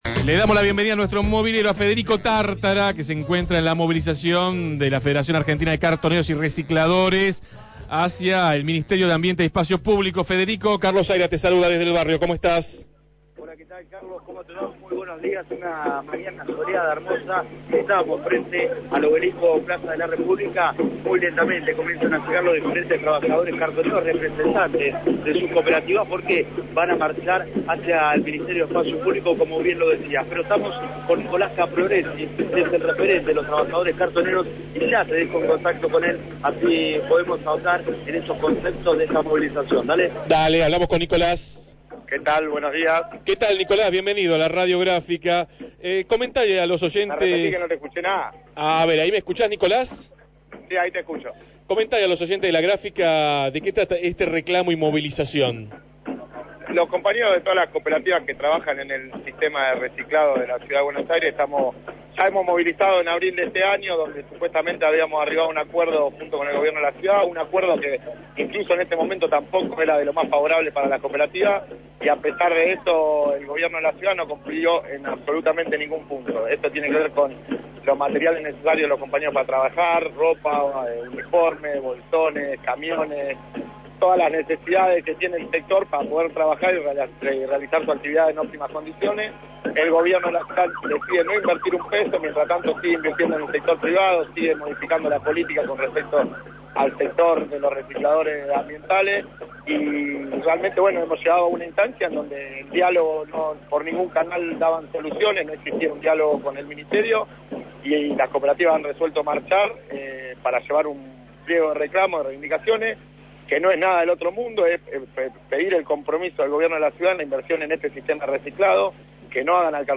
Radio Gráfica cubrió la movilización realizada por la Federación Argentina de Cartoneros y Recicladores hacia el Ministerio de Ambiente y Espacio Público, tras el abandono del sistema de reciclado con inclusión social por parte del Gobierno de la Ciudad de Buenos Aires.